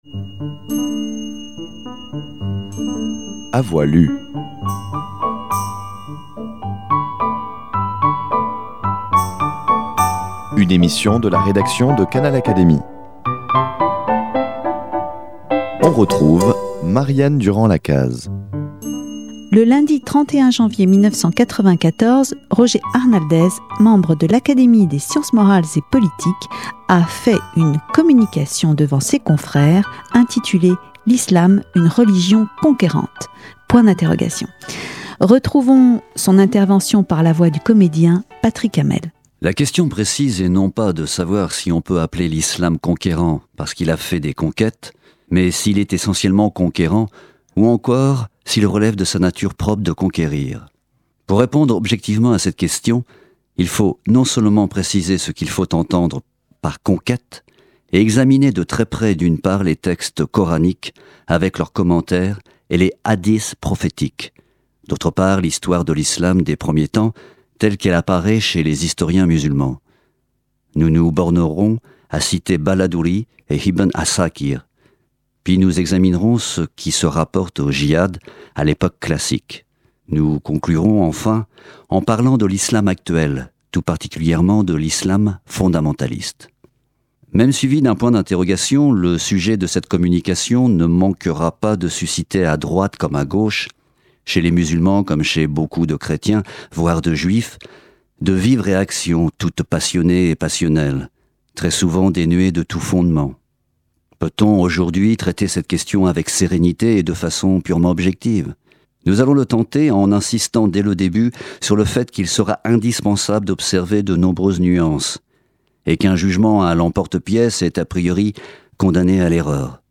Canal Académie vous propose d’en écouter la lecture.